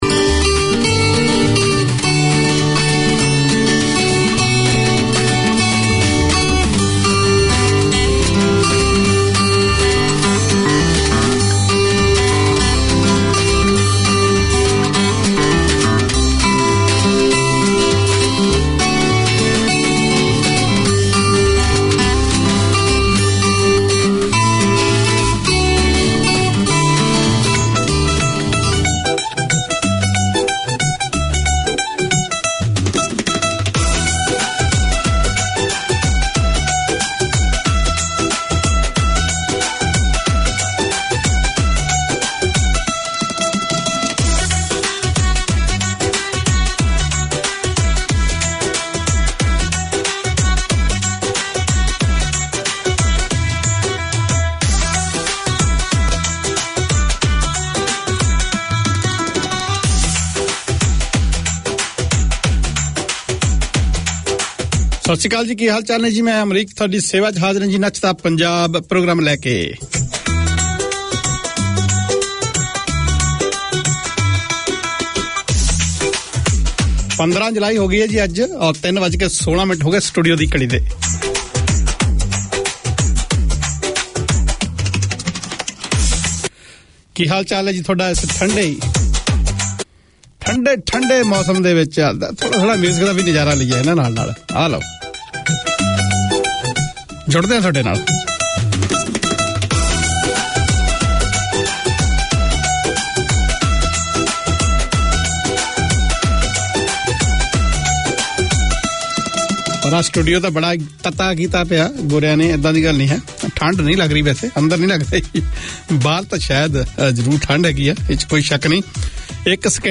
Nachda Punjab 3:15pm TUESDAY Community magazine Language